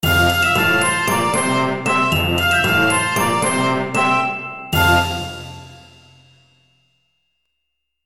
Success Resolution Video Game Fanfare Sound Effect
Category 🎮 Gaming
alert bonus clip fanfare game happy level music sound effect free sound royalty free Gaming